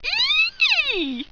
Index of /tactics/sfx/pain/sultry
eeeee.wav